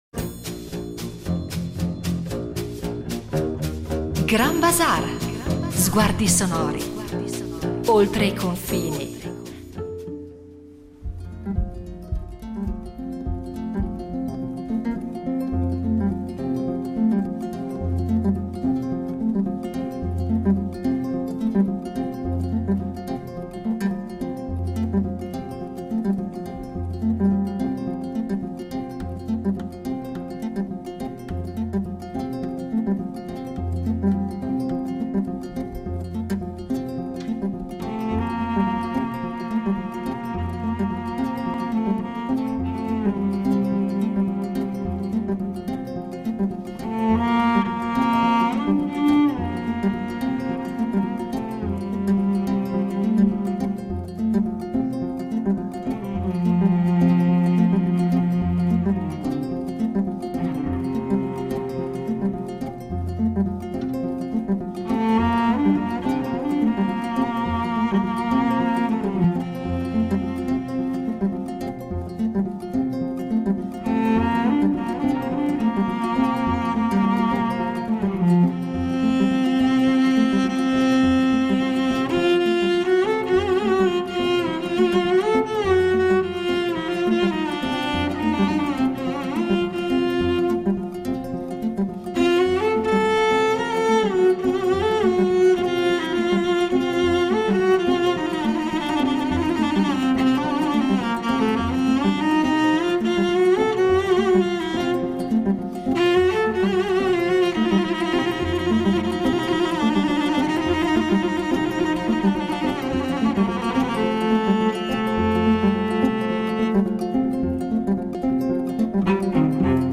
Attraverso racconti, esempi sonori e riflessioni, esploreremo le funzioni sociali del griot, la sua formazione, le tecniche di narrazione e le trasformazioni di questa figura nell’Africa contemporanea e nella diaspora. Ascolteremo brani di griot storici e contemporanei, scoprendo come questa forma d’arte orale – trasmessa per via ereditaria e spesso riservata a caste specializzate – sia tutt’altro che immobile: capace di reinventarsi, di parlare di attualità, di mescolare tradizione e modernità.